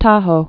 (tähō), Lake